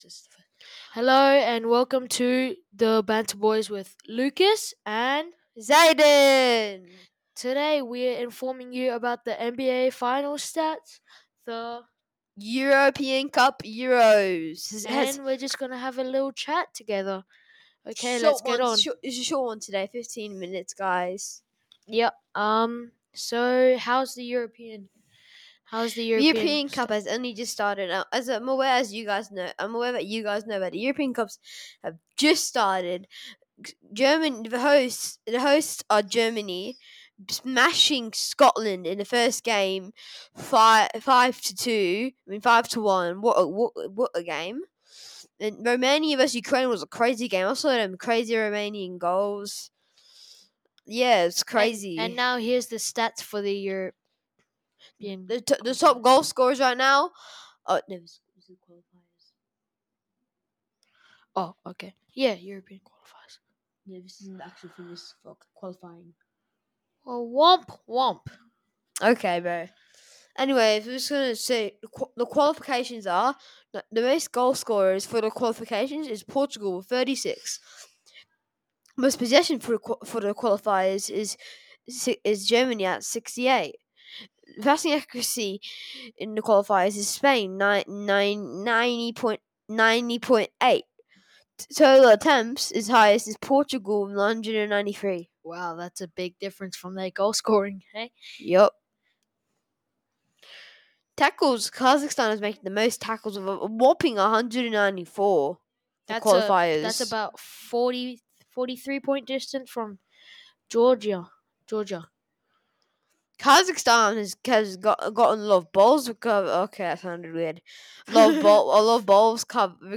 Hello, we welcome you to sit down and relax and enjoy some friends talk about sports news and share the latest interesting gossip thru the streets.